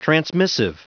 Prononciation du mot transmissive en anglais (fichier audio)
Prononciation du mot : transmissive